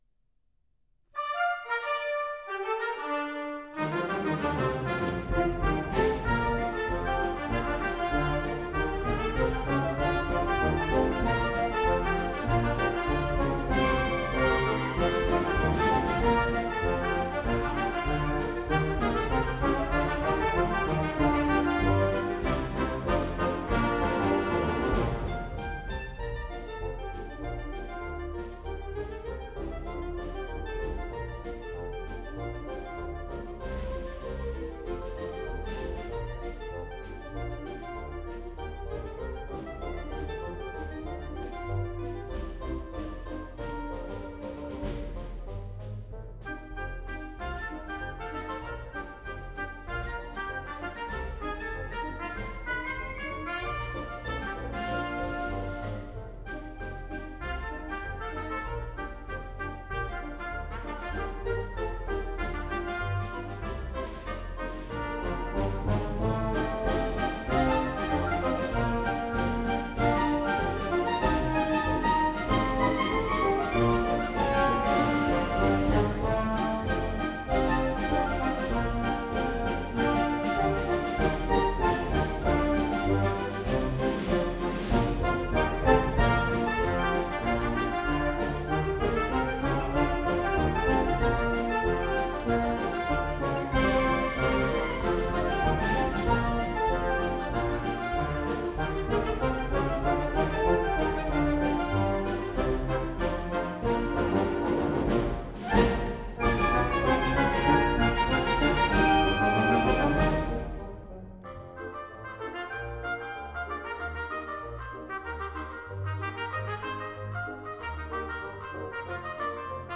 Album No. 14 | Recorded 1994